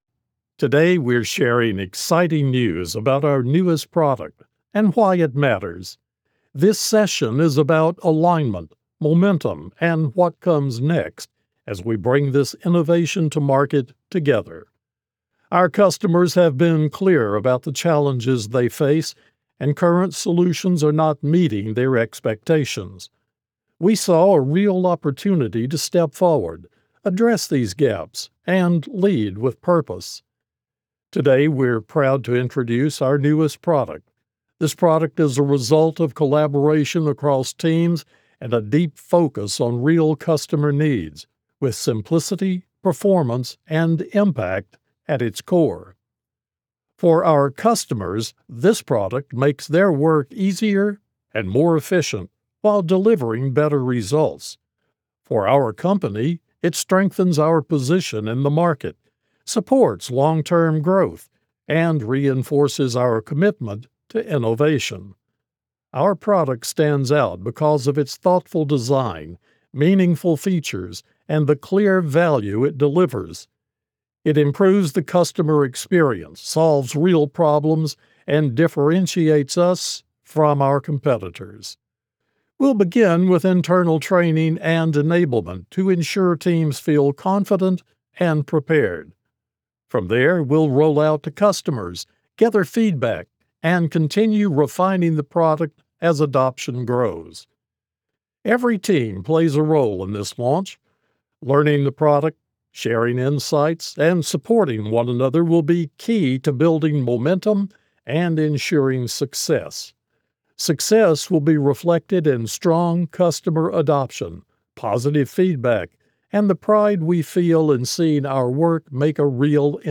E=learning, Corporate & Industrial Voice Overs
Adult (30-50) | Older Sound (50+)